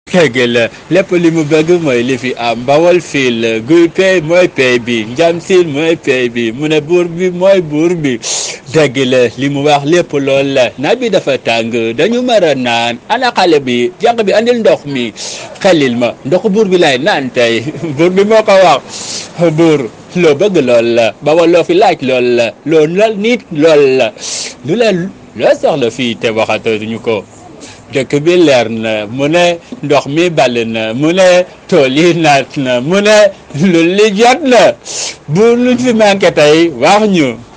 Musique traditionnelle africaine